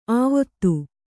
♪ āvattu